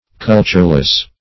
Cultureless \Cul"ture*less\, a. Having no culture.